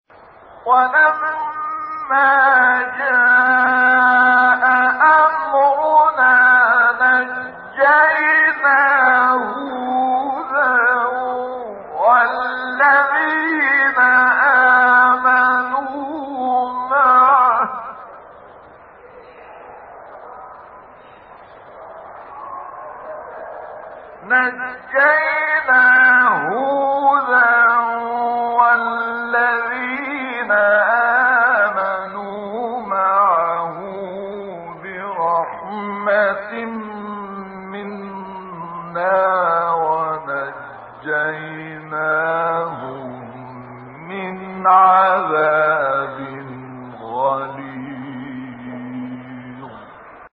گروه شبکه اجتماعی: مقاطعی صوتی از تلاوت سوره هود با صوت عبدالفتاح شعشاعی که در مقام‌های مختلف اجرا شده است، ارائه می‌شود.
این فرازها از تلاوت سوره هود می‌باشد و در مقام های حجاز، نهاوند، رست، صبا، عجم، چهارگاه و سه‌گاه اجرا شده‌اند.
مقام سه‌گاه